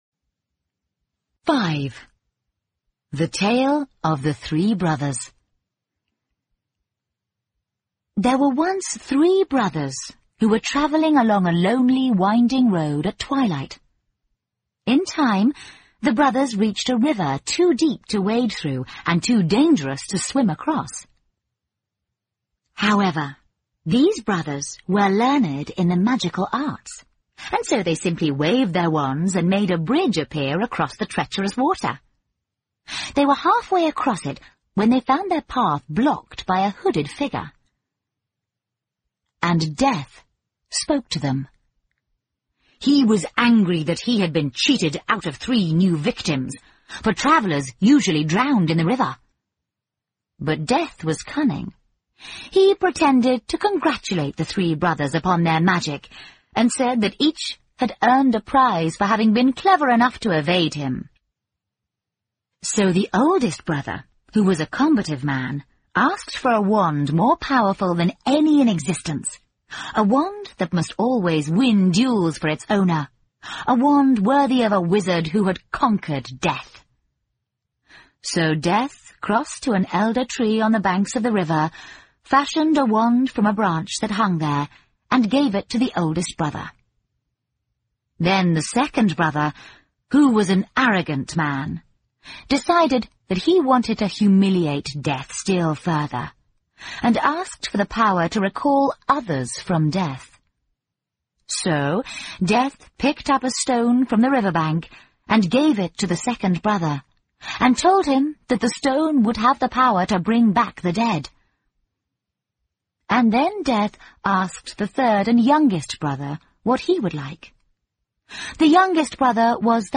在线英语听力室诗翁彼豆故事集 第24期:三兄弟的传说(1)的听力文件下载,《诗翁彼豆故事集》栏目是著名的英语有声读物，其作者J.K罗琳，因《哈利·波特》而闻名世界。